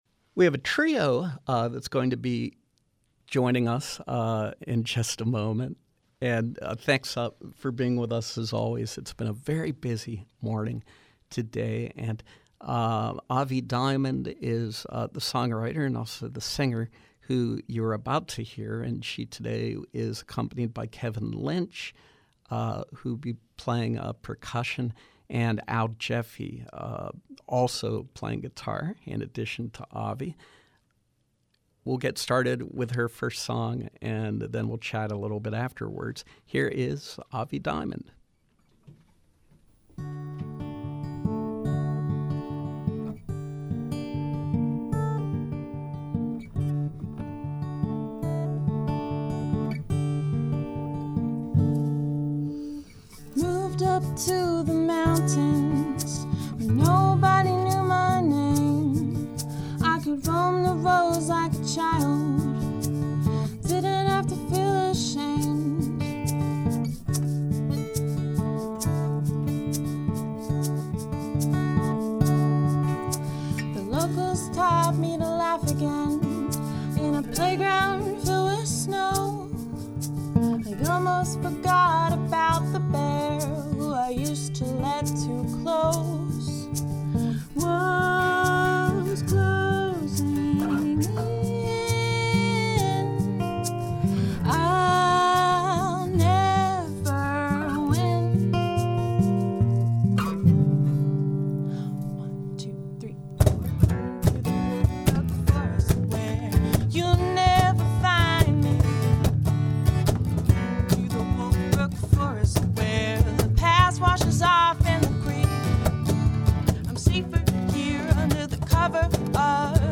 Pittsburgh-based songwriter and jazz singer
guitar
cajon